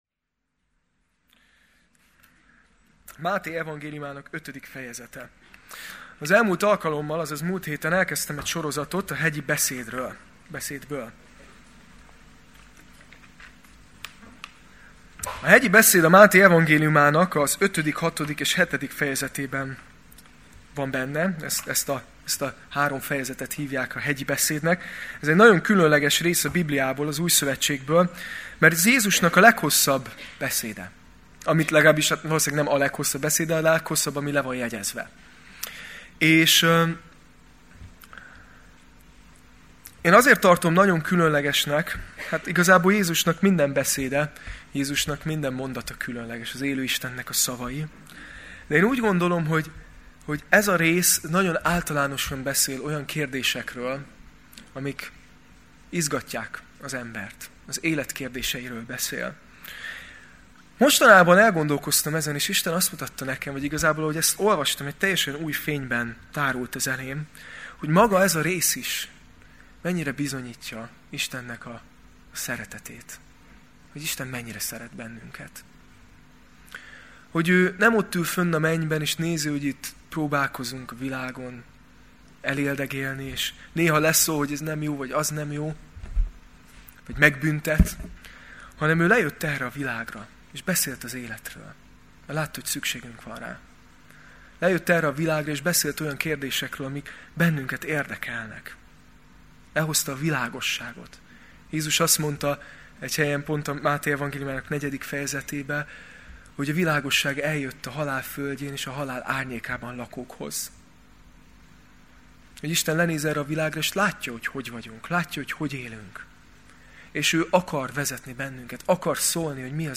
Alkalom: Vasárnap Este